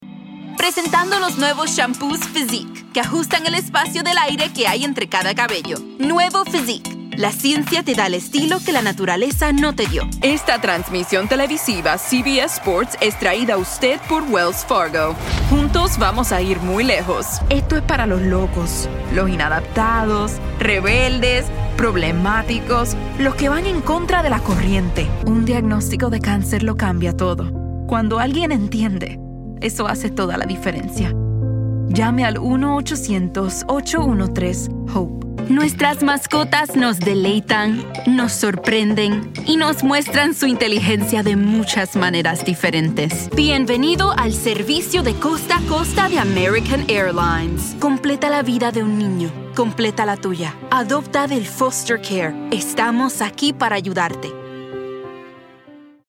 Spanisch (Puertoricanisch)
Konversation
Cool